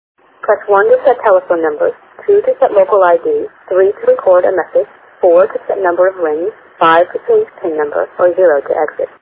VM500-5 Voice Demonstration